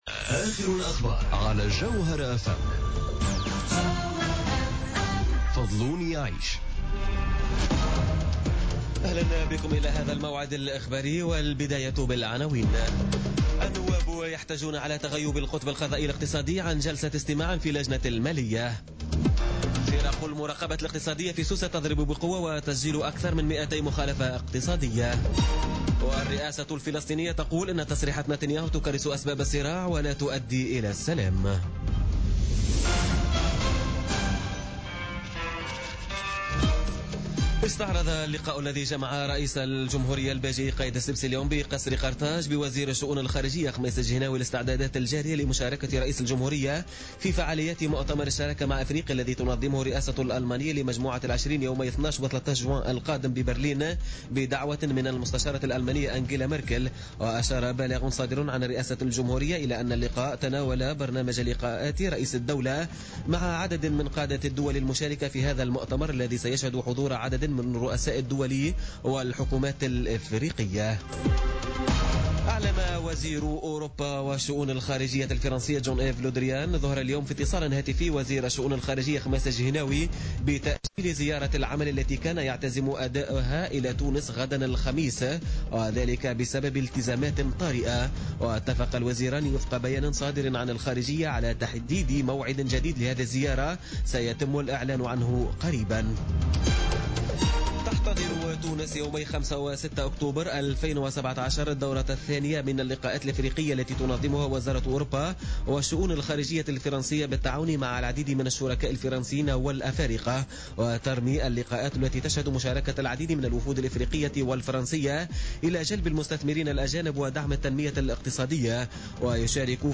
نشرة أخبار السادسة مساء ليوم الأربعاء 31 ماي 2017